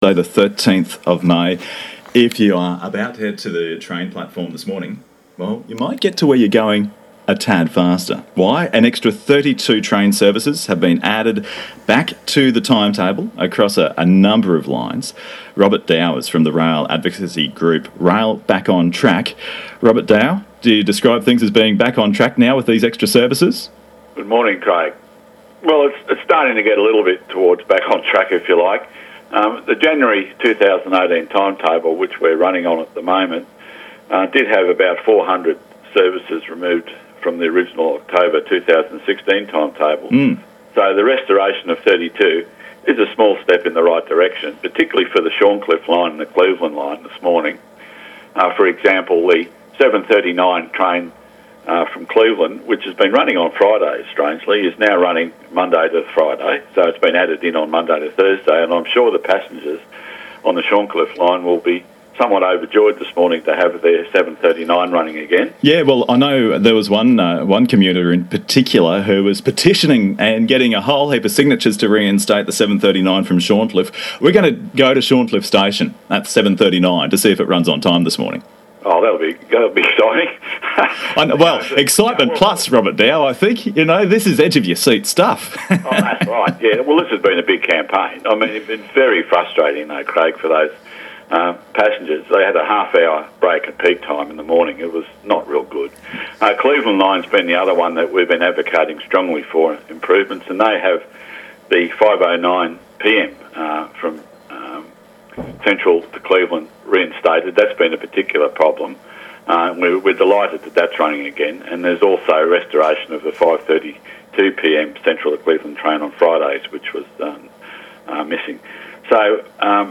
Interview -->